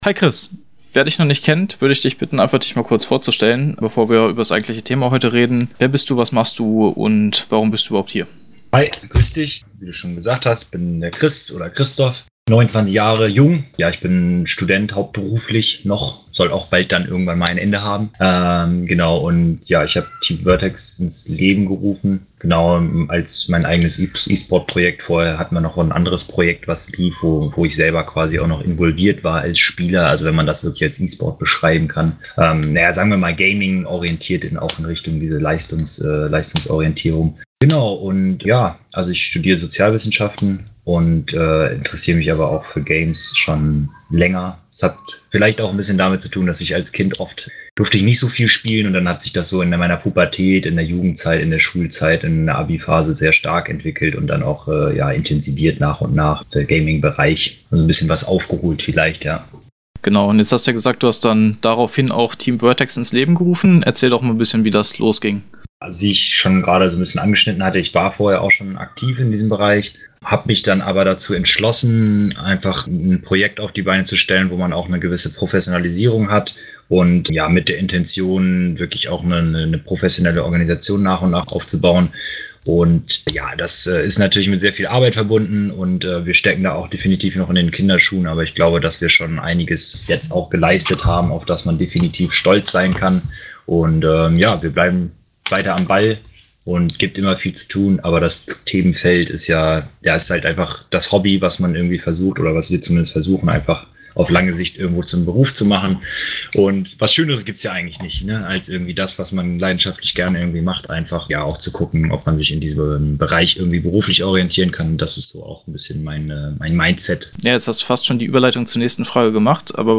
interview-team-vertex-samsung-deal.mp3